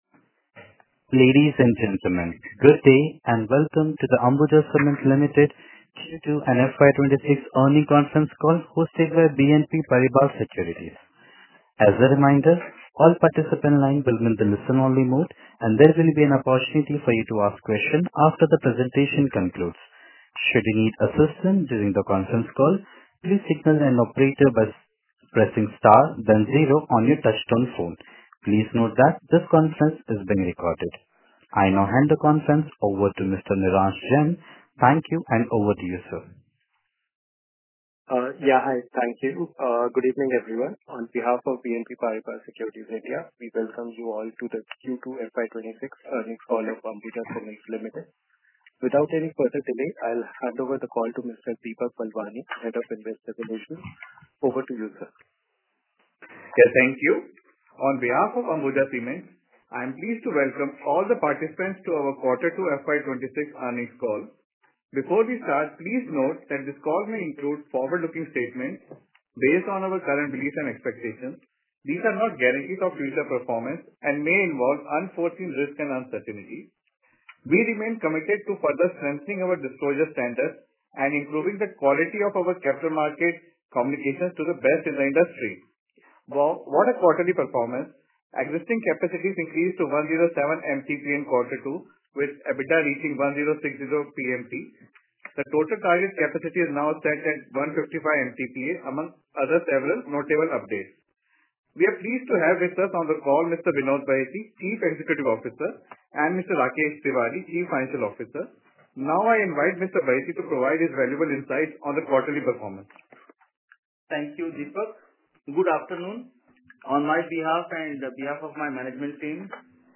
Recording Investorcall 02.05.2024